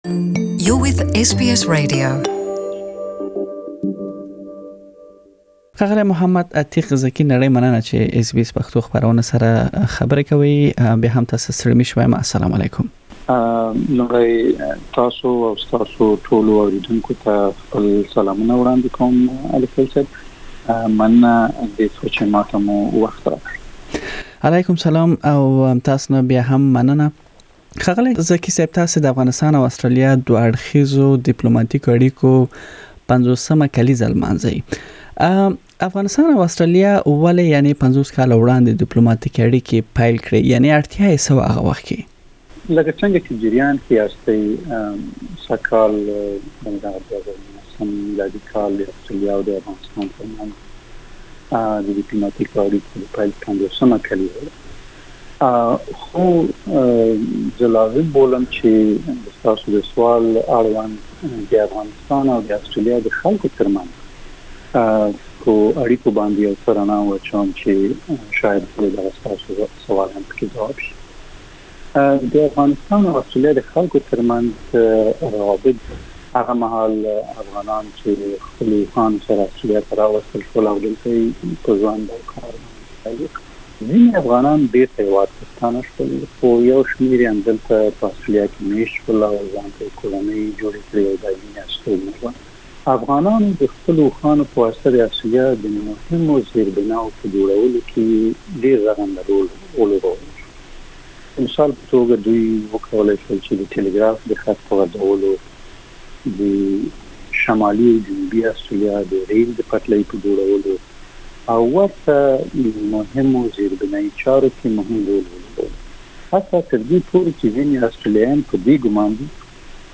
Speaking with SBS Pashto, the Deputy Afghan Ambassador Mohammad Ateeq Zaki says, the bilateral relationship between the two countries is stronger than ever. Mr. Zaki says Australia is providing annually around $180 millions of aid to Afghanistan.